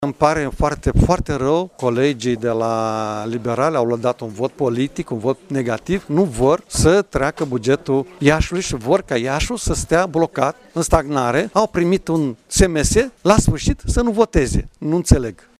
În replică, președintele CJ Iași, social-democratul Maricel Popa, a declarat că pe marginea bugetului, în ultimele 24 de ore, au avut loc patru dialoguri între grupurile din Consiliu, durata cumulată a acestora depășind 6 ore, iar votul liberalilor se datorează unui sms primit în timpul ședinței: